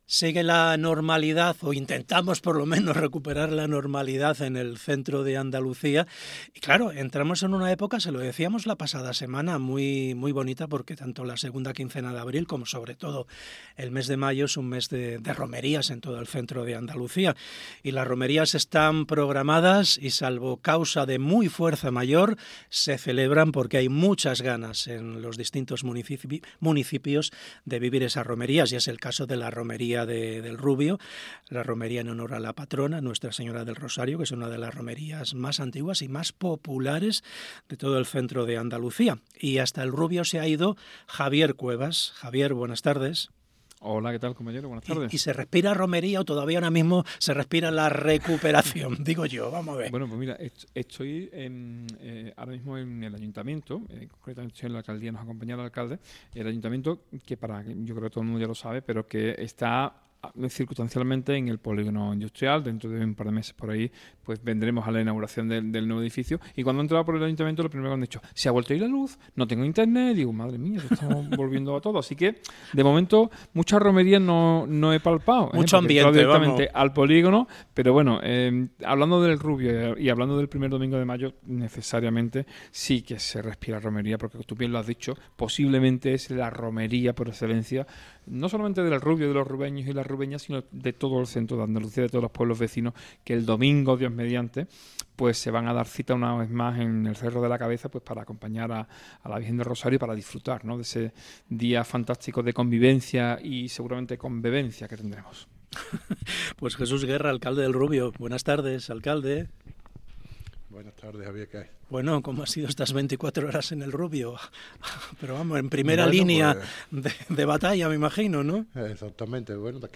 Entrevista Jesús Guerra Romería El Rubio 2025 - Andalucía Centro
Jesús Guerra, alcalde de El Rubio ha atendido a SER Andalucía Centro